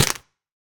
Minecraft Version Minecraft Version 1.21.5 Latest Release | Latest Snapshot 1.21.5 / assets / minecraft / sounds / block / mangrove_roots / step6.ogg Compare With Compare With Latest Release | Latest Snapshot
step6.ogg